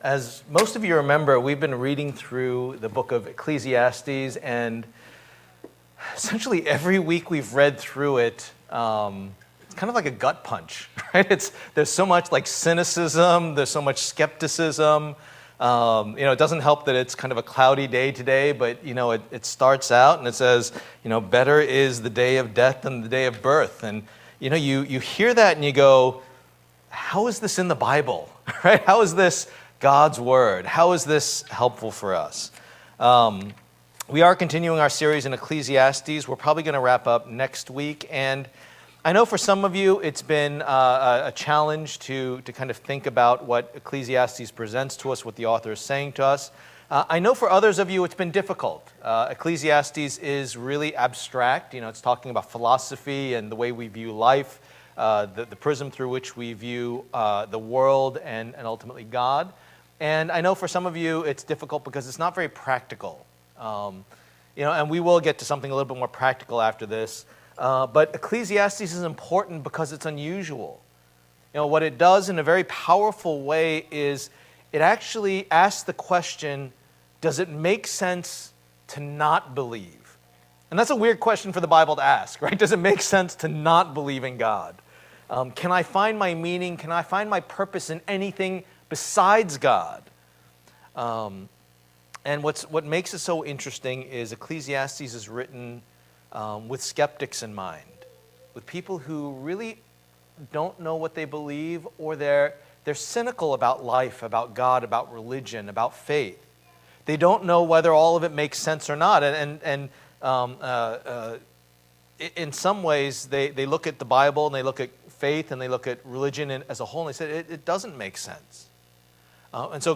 Passage: Ecclesiastes 7:1-18 Service Type: Lord's Day